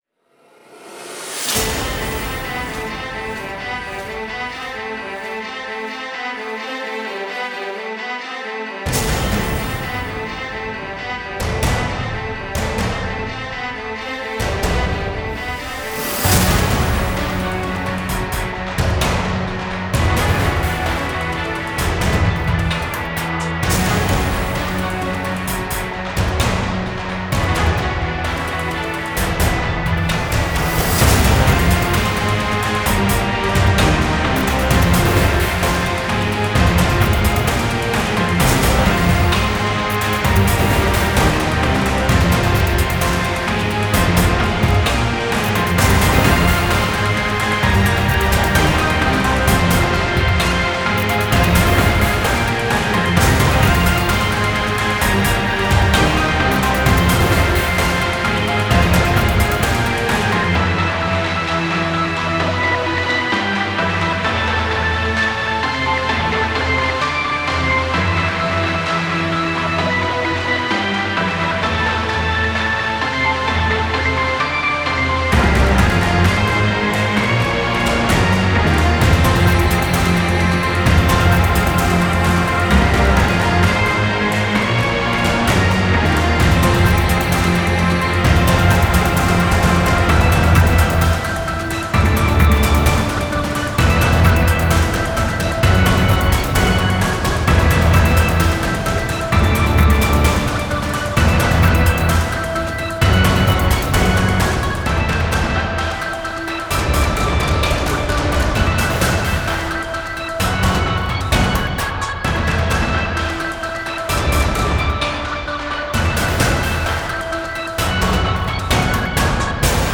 Genre: Sympho, Industrial
Instruments: guitars, programming